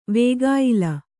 ♪ vēgāyila